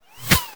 bullet_flyby_01.wav